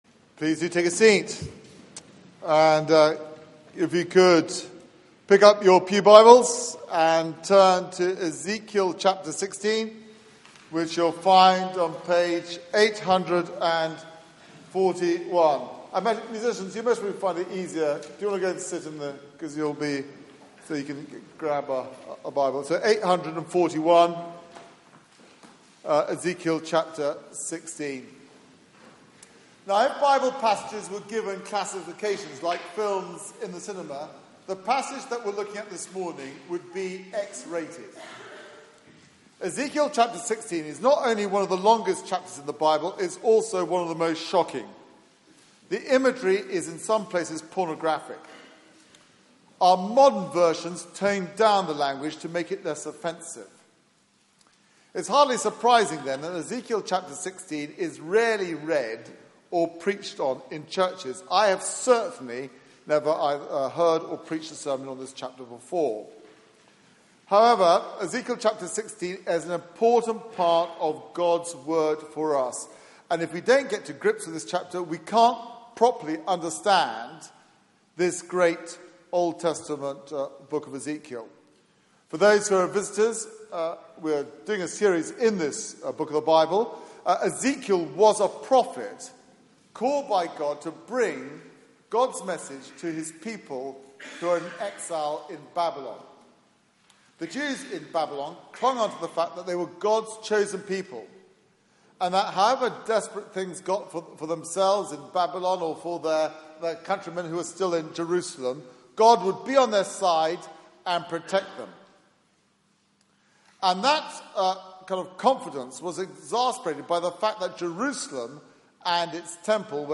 Media for 9:15am Service on Sun 10th Jan 2016
Series: Ezekiel - Hope for the Hopeless Theme: A love story Sermon